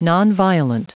Prononciation du mot nonviolent en anglais (fichier audio)